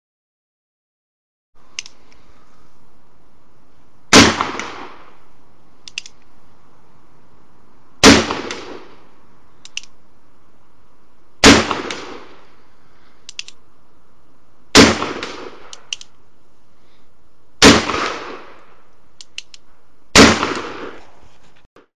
44_black_powder
1858 44 bang black cal explosion gun gunshot sound effect free sound royalty free Memes